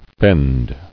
[fend]